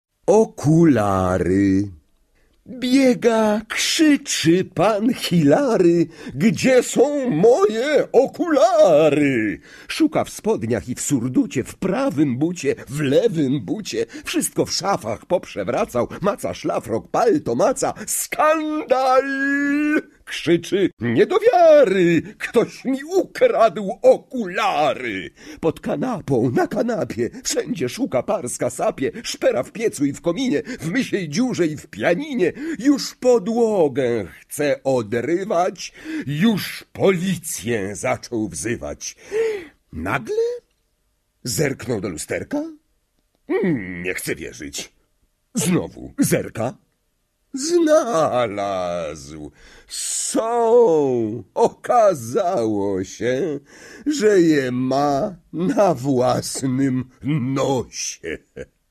В исполнении Петра Фрончевского слушаем всё стихотворение целиком: